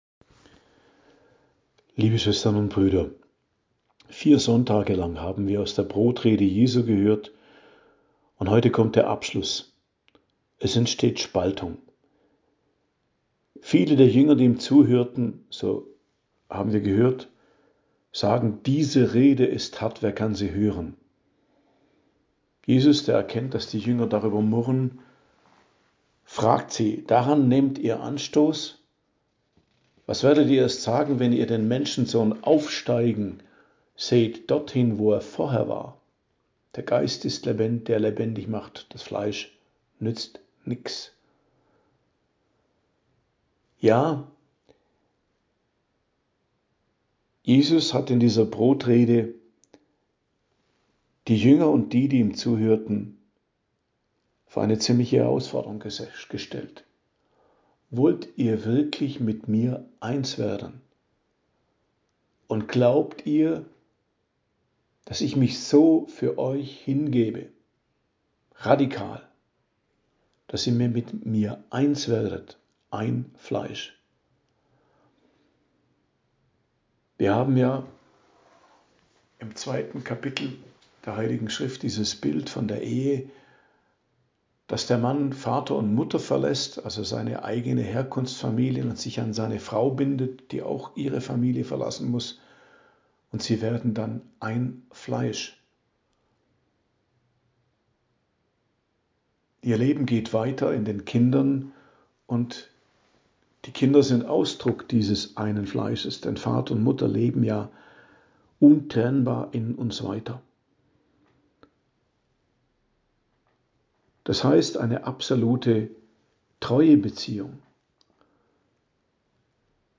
Predigt zum 21. Sonntag im Jahreskreis, 25.08.2024 ~ Geistliches Zentrum Kloster Heiligkreuztal Podcast